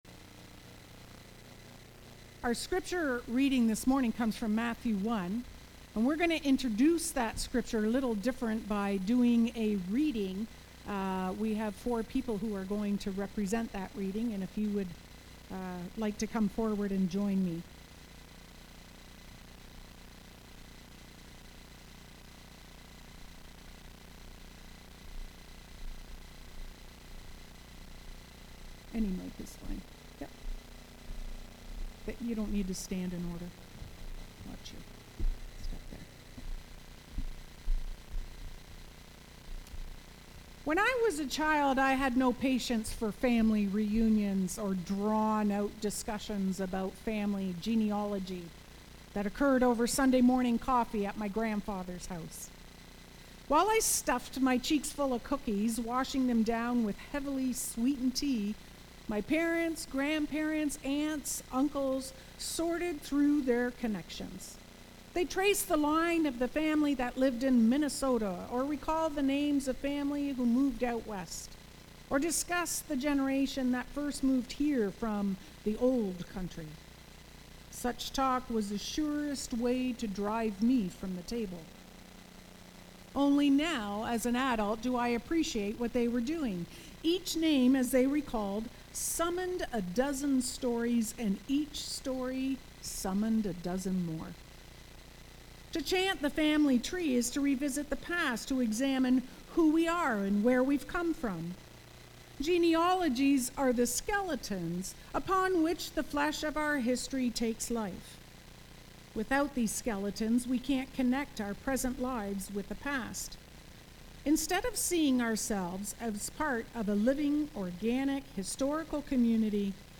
Knox Binbrook worship service November 26, 2023